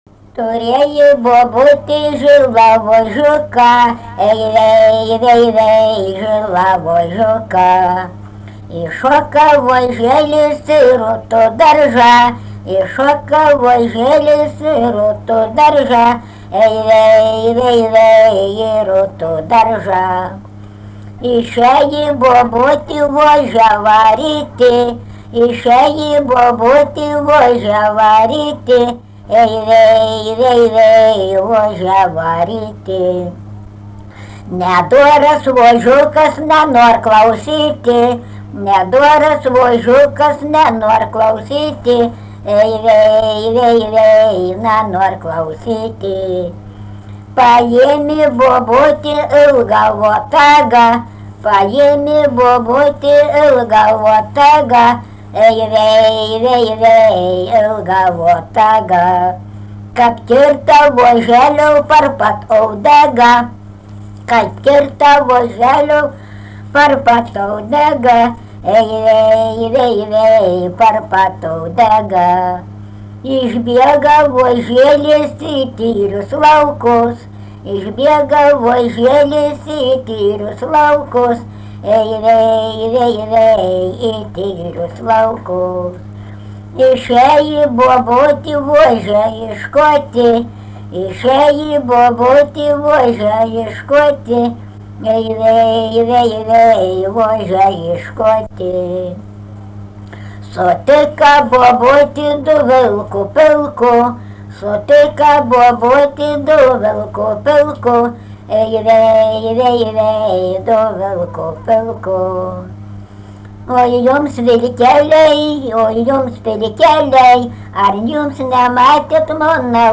daina, vaikų
Barvydžiai
vokalinis